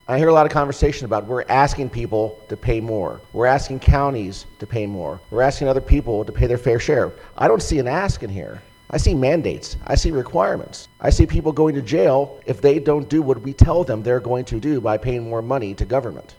Cecil and Harford Counties Delegate Mike Griffith spoke about the large amount of program funding being pushed onto counties in Maryland’s budget including over a billion dollars more in spending for education, as well as $100 million dollars in teacher pensions with the caveat that counties can raise local taxes.